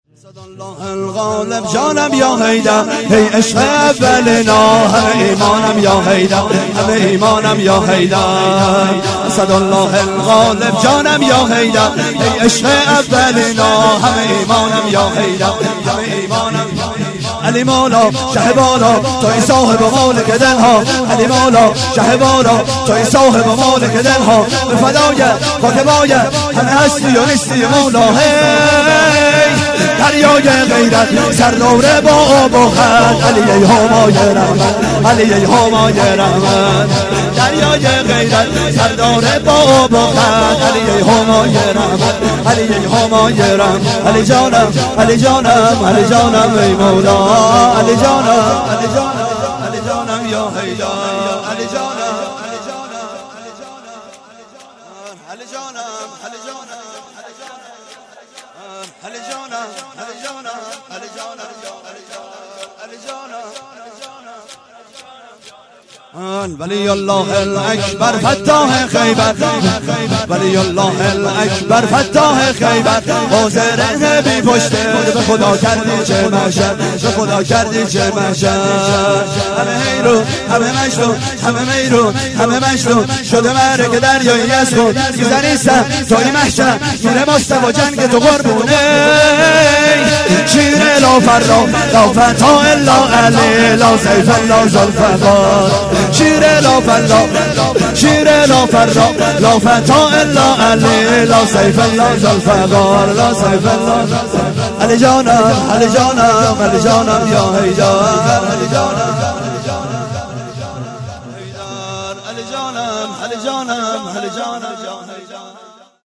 0 0 شور - اسدالله الغالب جانم یا حیدر
هفتگی - وفات حضرت ام البنین س - جمعه 11 اسفند